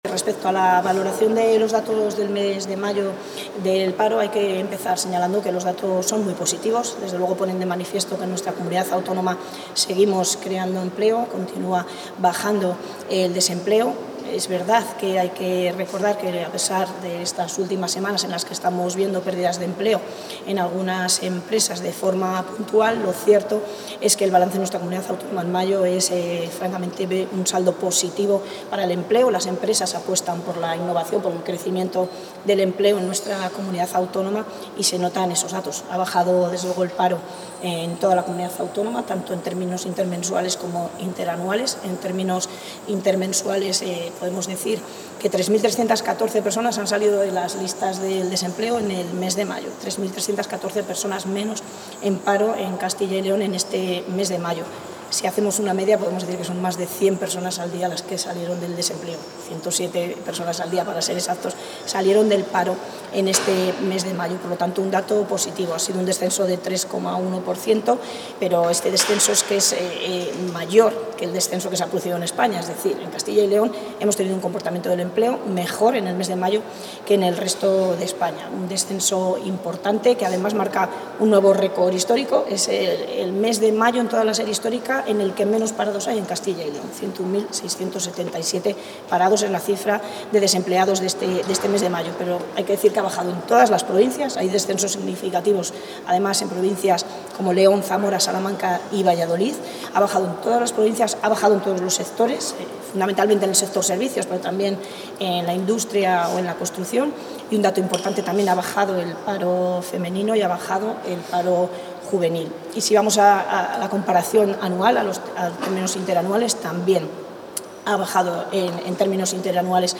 Valoración de los datos del paro registrado de mayo de 2025 Contactar Escuchar 3 de junio de 2025 Castilla y León | Consejería de Industria, Comercio y Empleo La consejera de Industria, Comercio y Empleo, Leticia García, ha valorado hoy los datos de paro registrado correspondientes al mes de mayo de 2025.